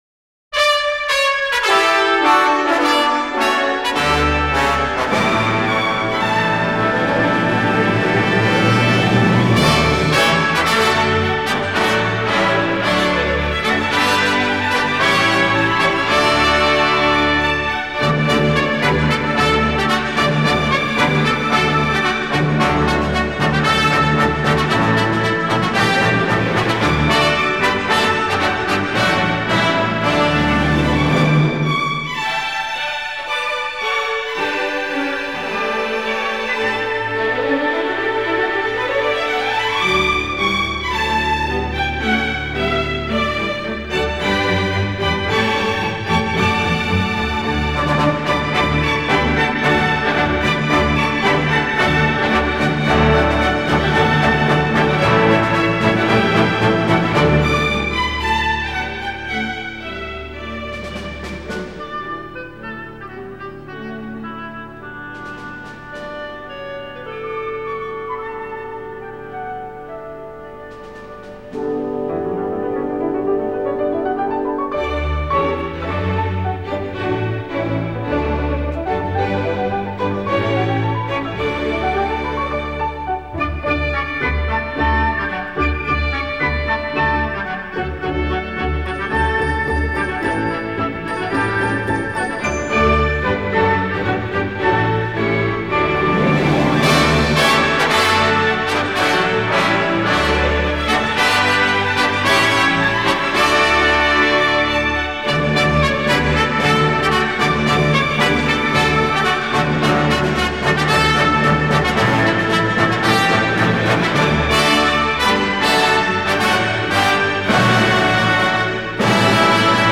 оркестр